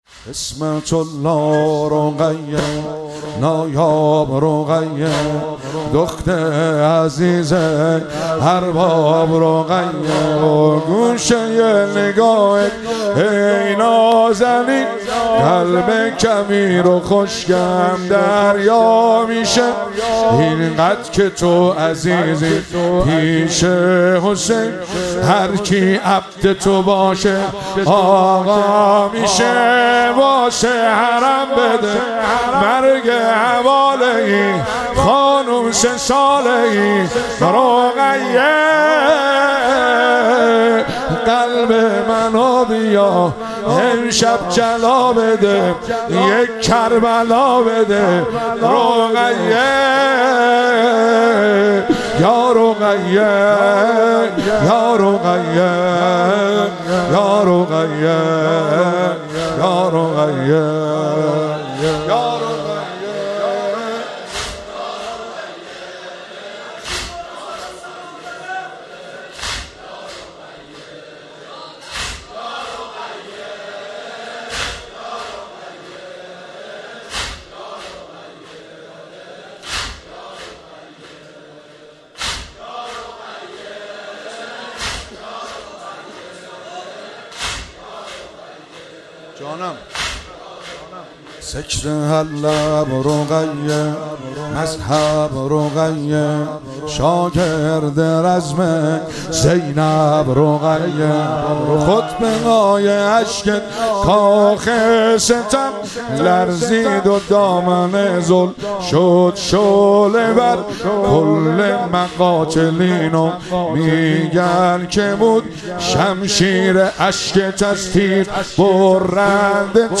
مراسم شهادت امام سجاد(ع)- شهریور 1401
واحدتند- عصمت الله رقیه، نایاب رقیه